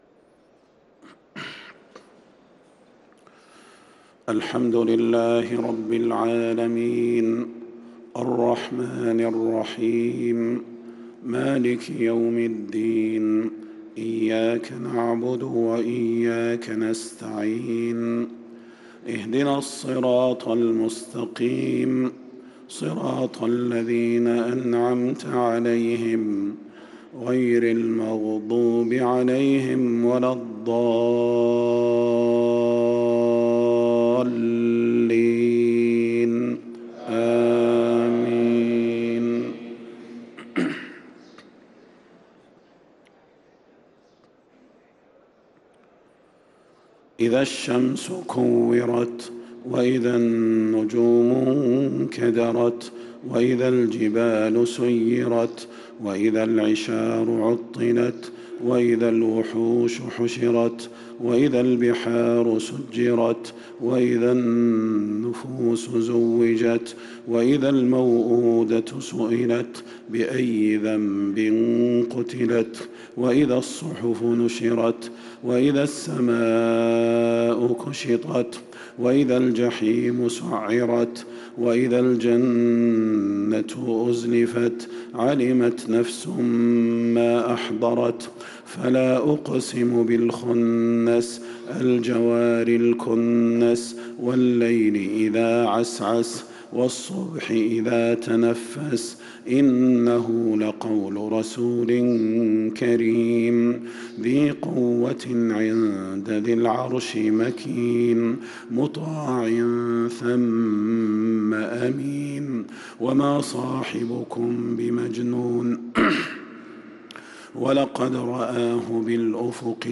صلاة الفجر للقارئ صلاح البدير 2 جمادي الآخر 1445 هـ
تِلَاوَات الْحَرَمَيْن .